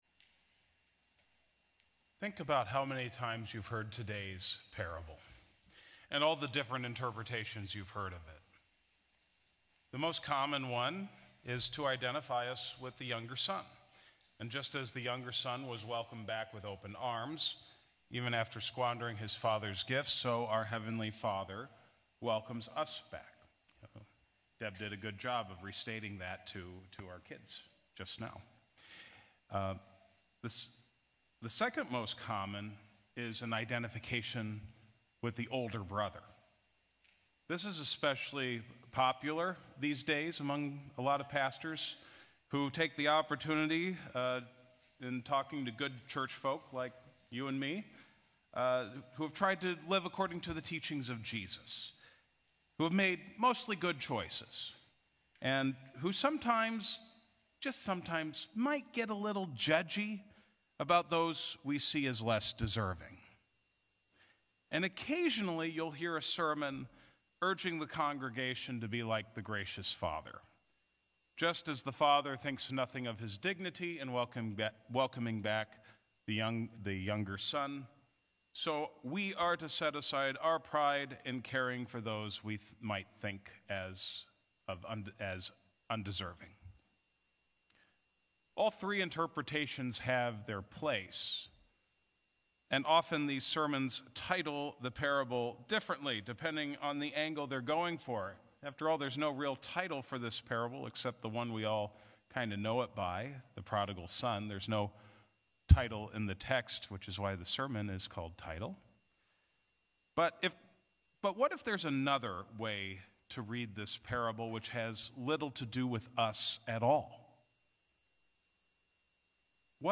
Sermons | Shalom Lutheran Church